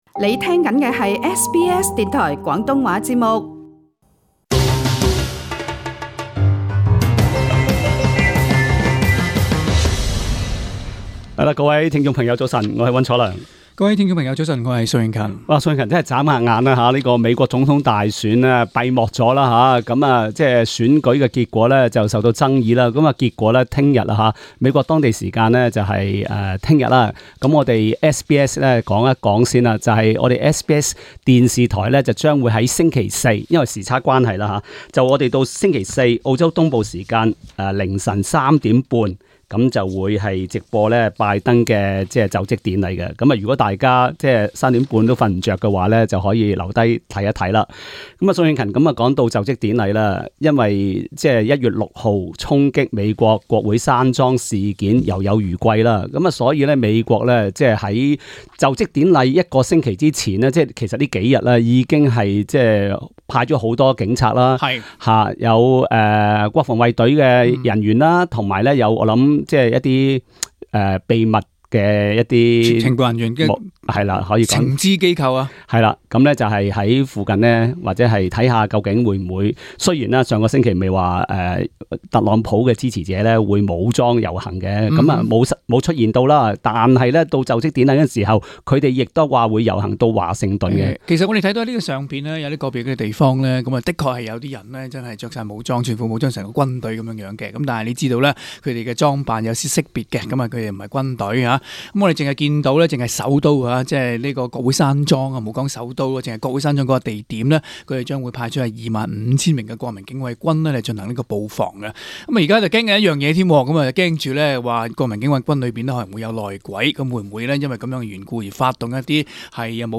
Source: AAP SBS广东话播客 View Podcast Series Follow and Subscribe Apple Podcasts YouTube Spotify Download (9.96MB) Download the SBS Audio app Available on iOS and Android 拜登的就职典礼，明天将于美国举行，这是美国一个关键时刻。